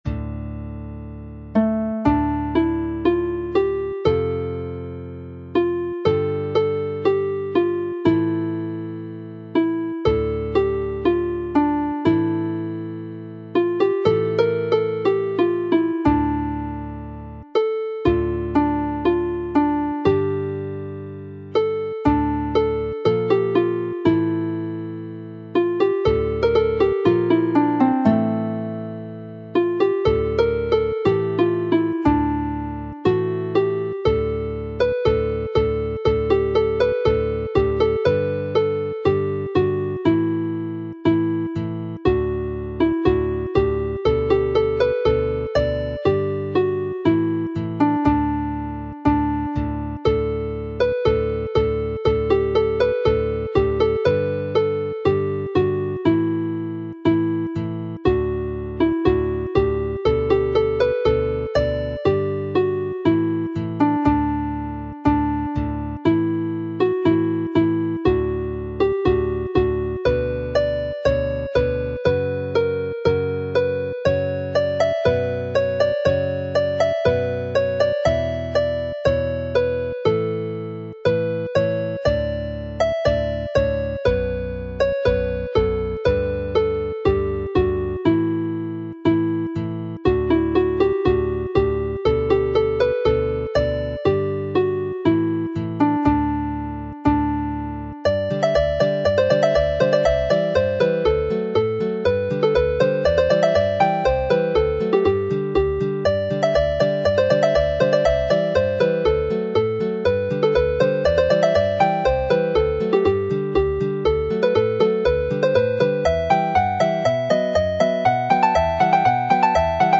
Set Bwthyn fy Nain - alawon pibau
Bwthyn fy Nain set - pipe tunes